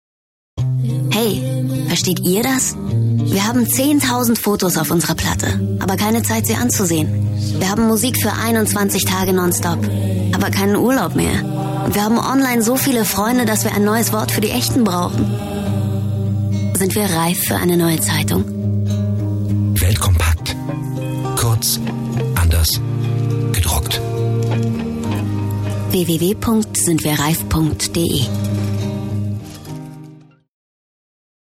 Sprecherin Werbung Stimme Welt Kompakt sachlich seriös sinnlich frech lieblich kindlich derb erotisch Hörbuch Dokumentation Reportage Synchron ausgebildet
Sprechprobe: Werbung (Muttersprache):
professional narrator and voice-over artist with a unique voice and expression